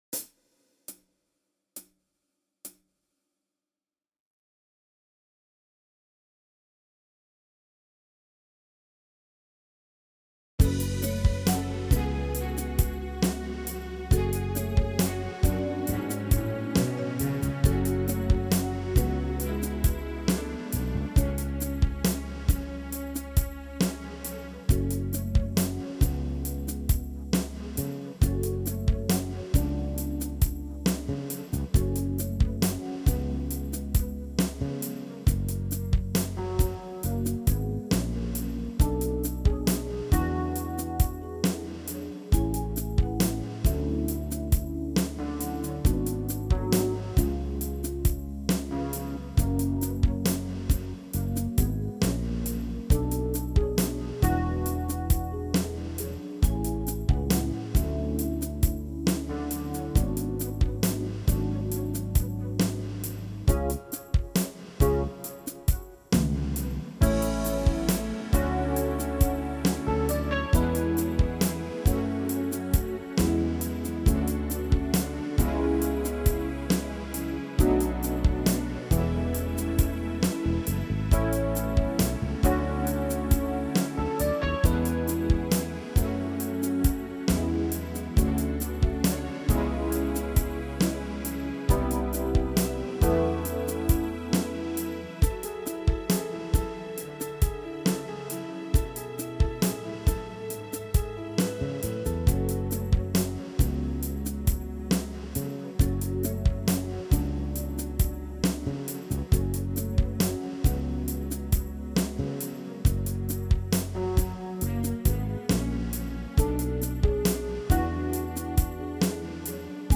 MIDI version
INSTRUMENTAL VERSION